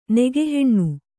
♪ nege heṇṇu